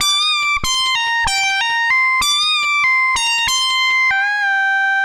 Index of /musicradar/80s-heat-samples/95bpm
AM_CopMono_95-C.wav